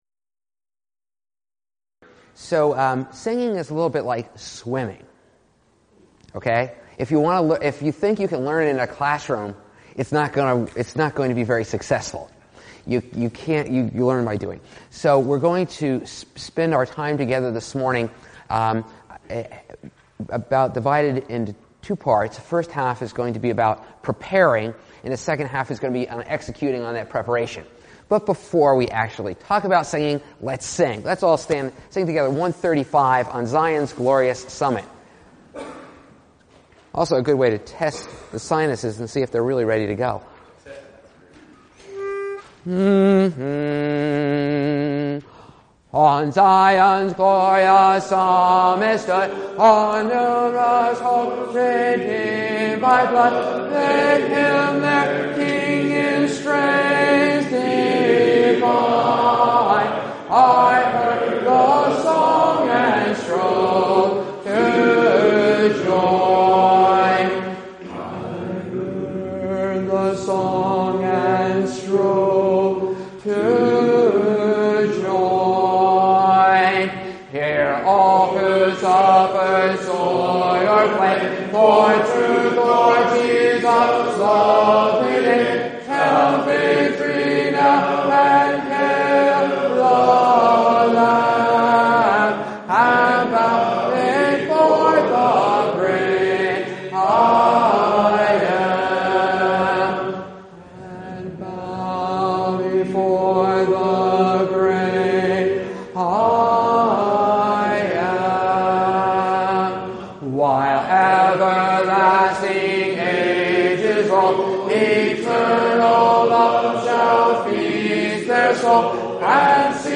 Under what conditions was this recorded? Event: 1st Annual Young Men's Development Conference